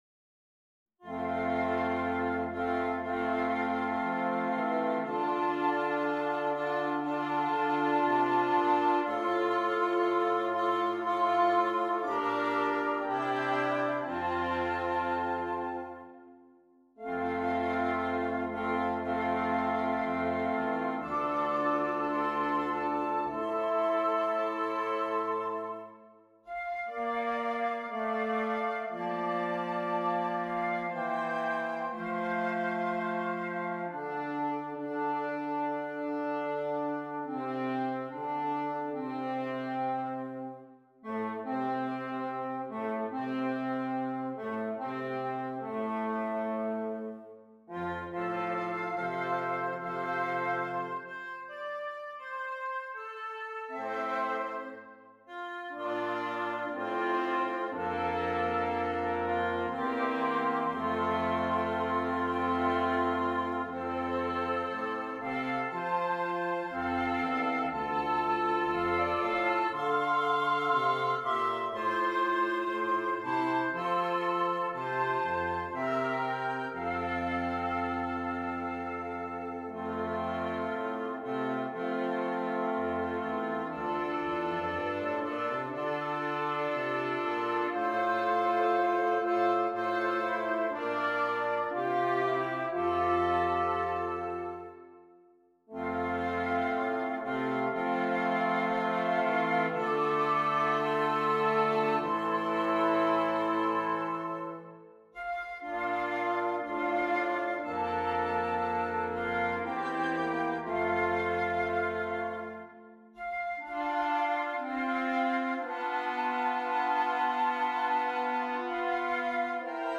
Woodwind Quintet
Traditional